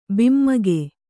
♪ bimmage